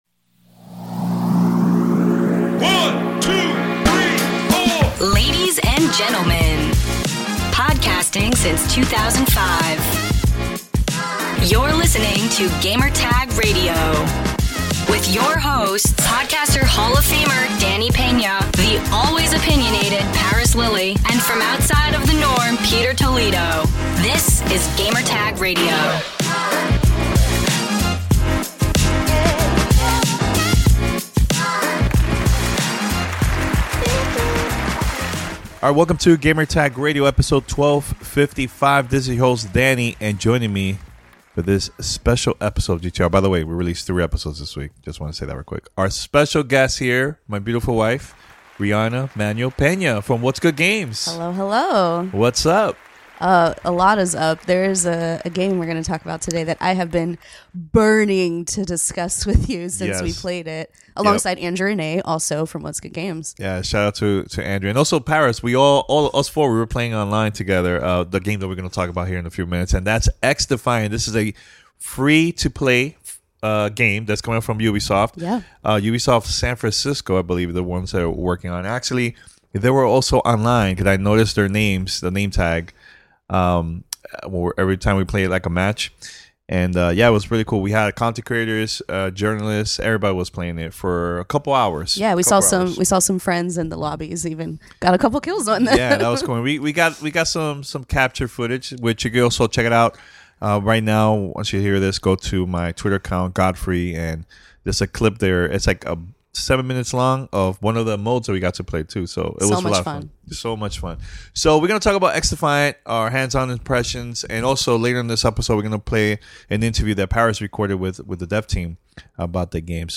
XDefiant Hands-On Impressions and Interview